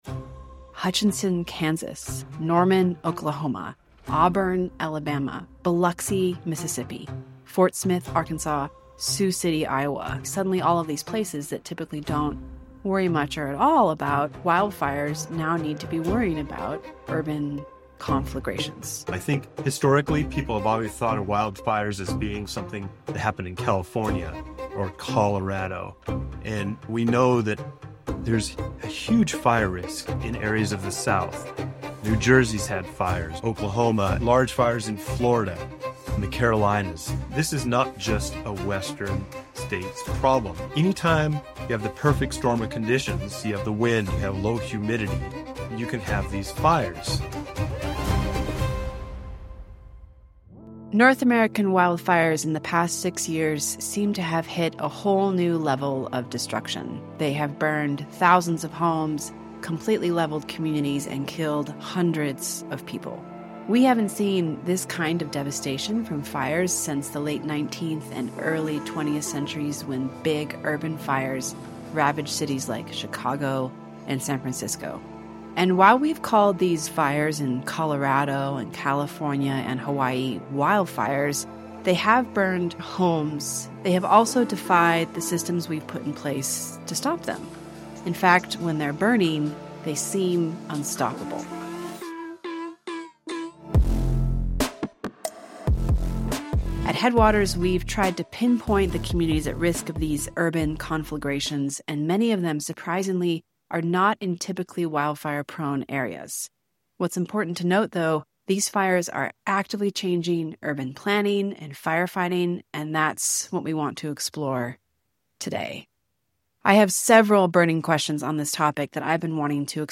Learn about new risk factors, the pivotal role of building codes, and how communities can adapt to these evolving threats. If you’re a planner, homeowner, or simply concerned about fire safety, this conversation is essential.